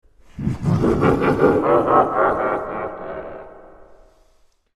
Звук смеха Картун Кэт